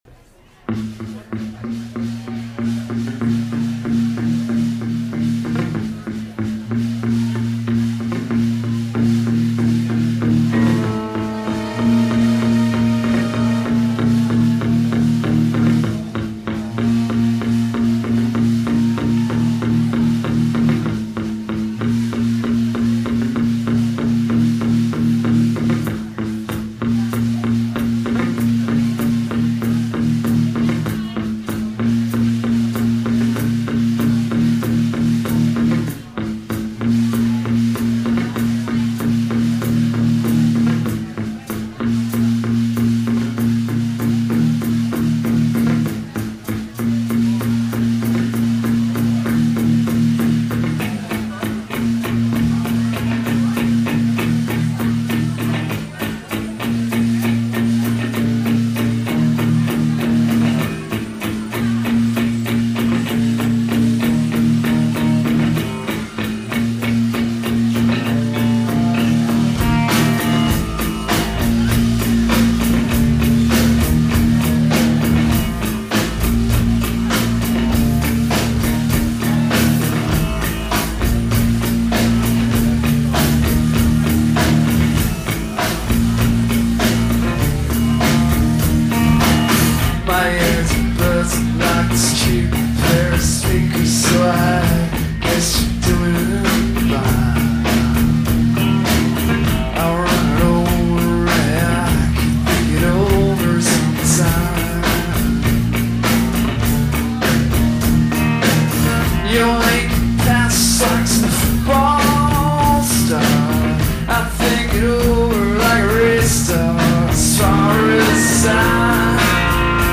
schuba's chicago september 2000